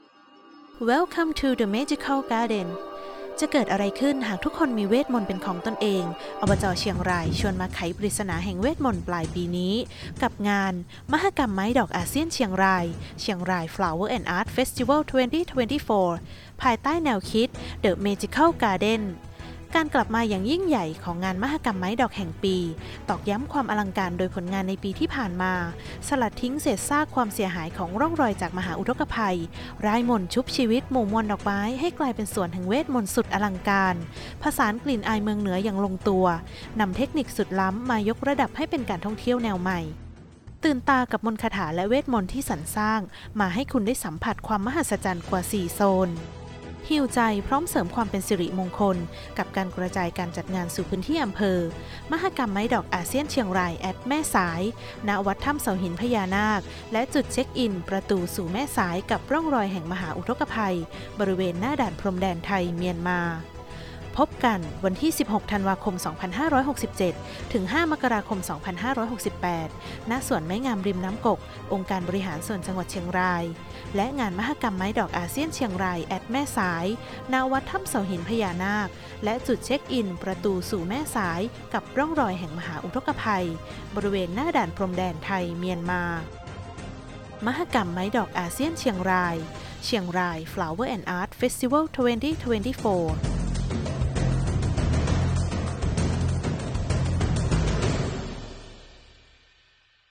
สปอตวิทยุมหกรรมไม้ดอกอาเซียนเชียงราย